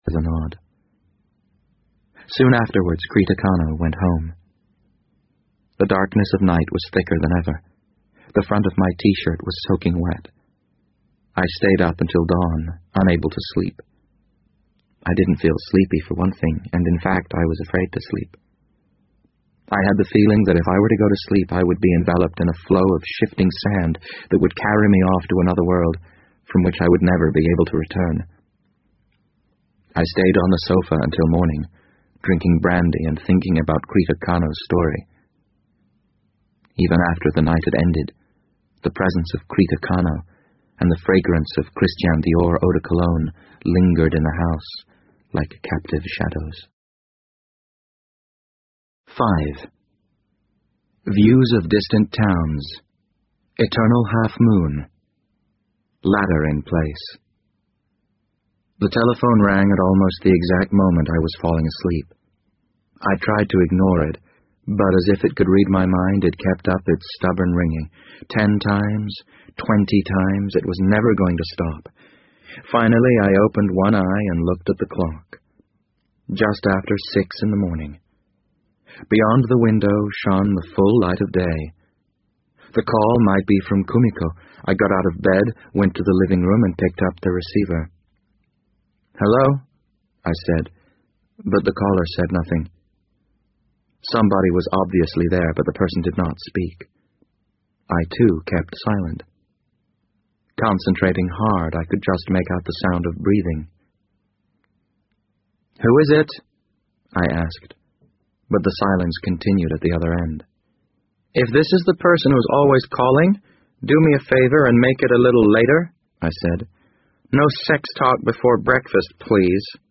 BBC英文广播剧在线听 The Wind Up Bird 006 - 6 听力文件下载—在线英语听力室